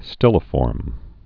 (stĭlə-fôrm)